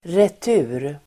Uttal: [ret'u:r]